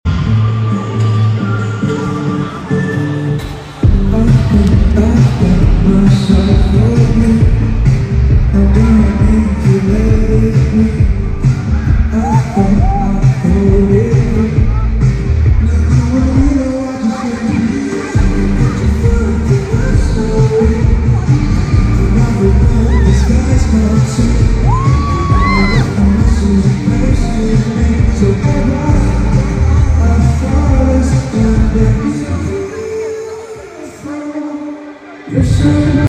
this video was taken from their live streaming back then.